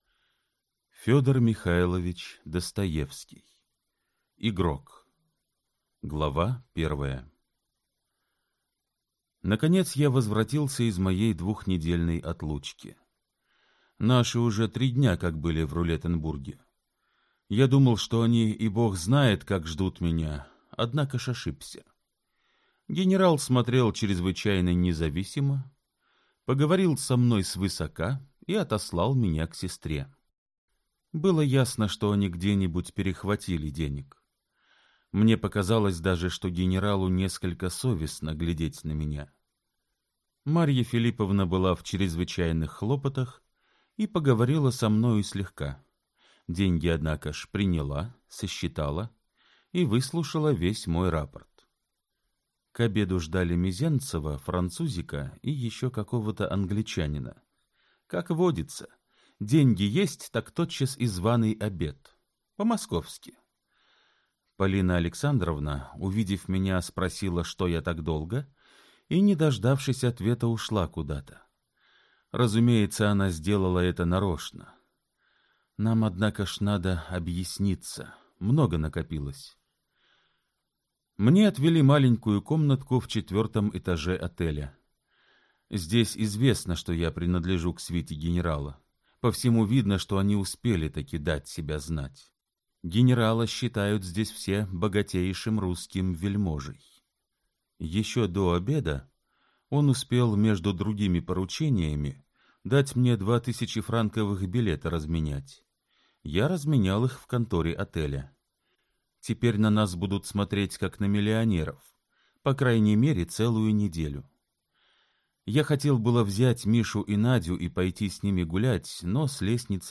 Аудиокнига Игрок. Дядюшкин сон. Скверный анекдот | Библиотека аудиокниг